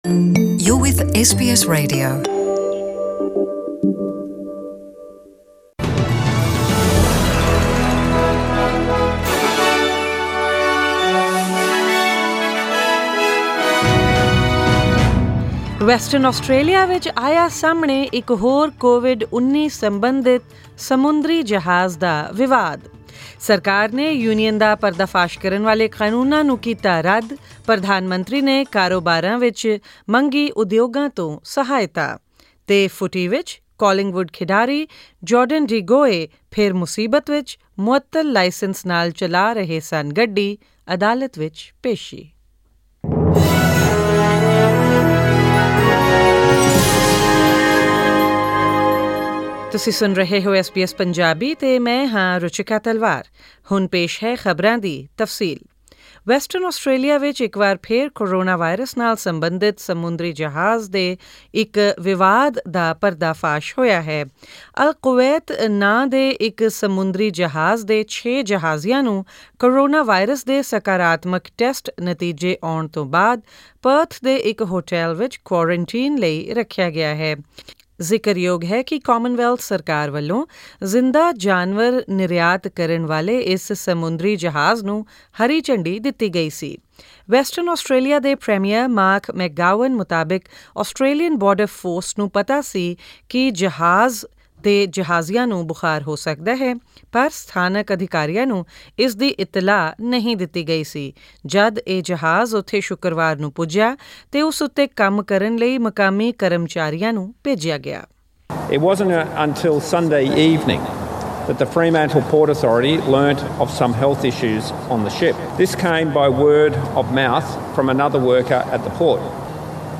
Presenting the major national and international news stories of today; including updates on sports, currency exchange rates and the weather forecast for tomorrow.